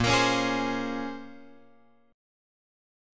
A# Minor 9th
A#m9 chord {6 x 8 6 9 8} chord